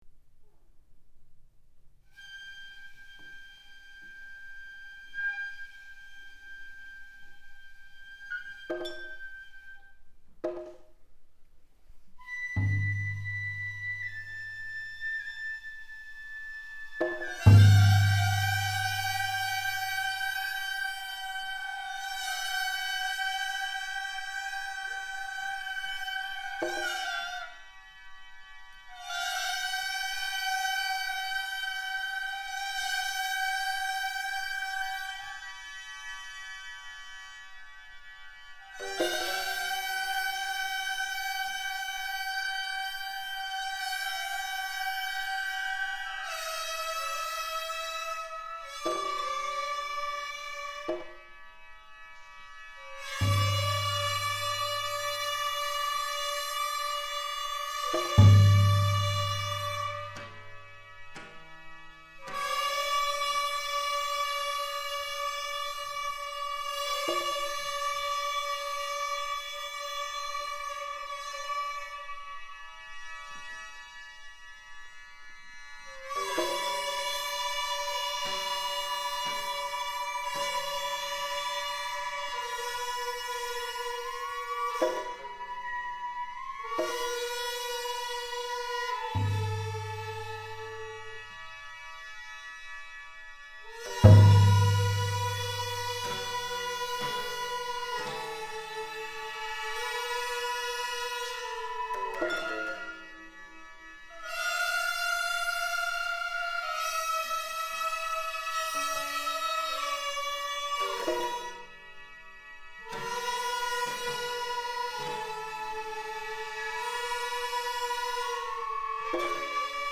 名古屋市千種文化小劇場にて
第九回合同雅楽演奏会
１．管弦
音取とは、主奏者による短い前奏曲で、
壱越調（いちこつちょう）から移調された曲（渡し物）
演奏：若竹雅楽会
舞台正面の客席２列目にＳｏｎｙ　Ｃ３７Ｐ改造が２本、
２ｃｈにてＨＤＤレコーダー２台に記録します。
あえてアナログミキサーで２ｃｈにして記録します。